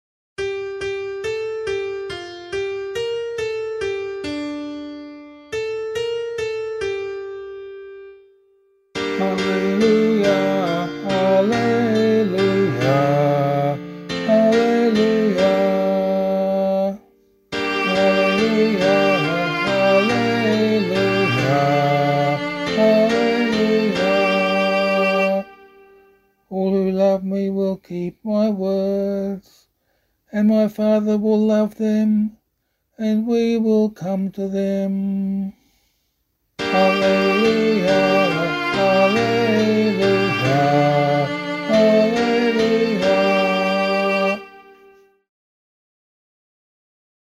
028 Easter 6 Gospel [LiturgyShare D - Oz] - vocal.mp3